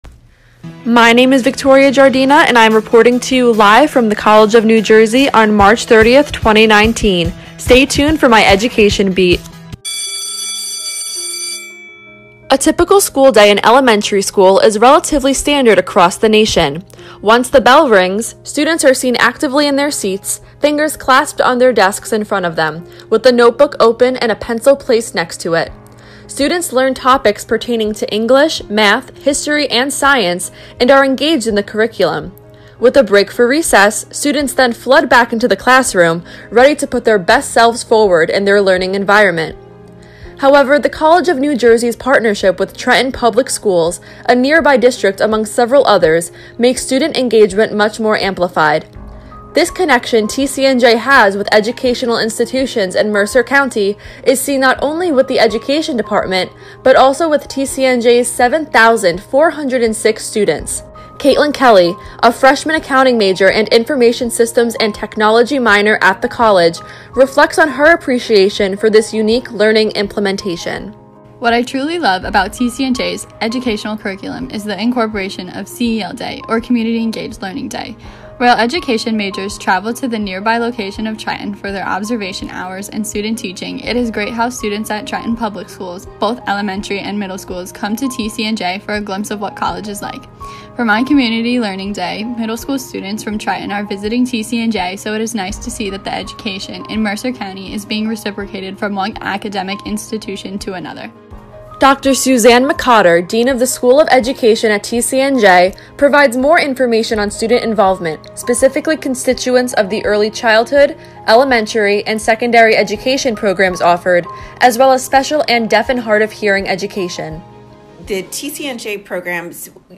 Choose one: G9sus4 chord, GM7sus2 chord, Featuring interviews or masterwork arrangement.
Featuring interviews